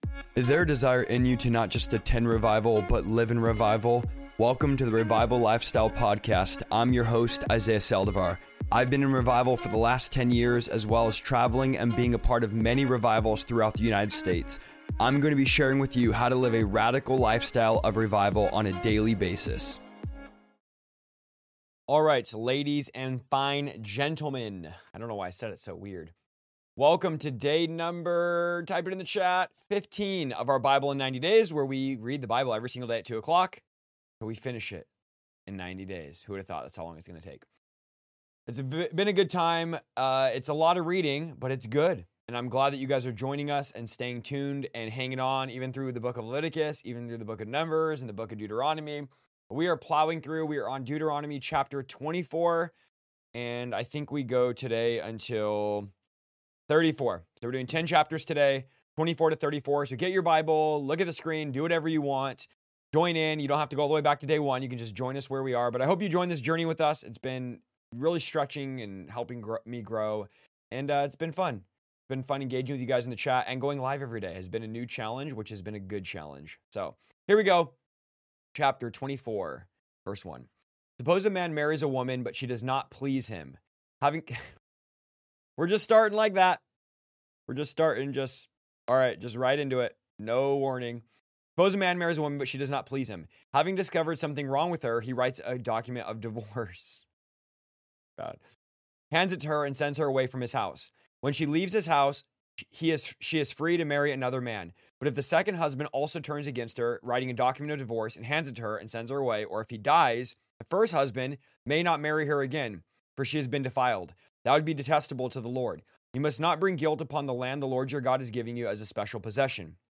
Call in live stream, where we answer any questions you might have!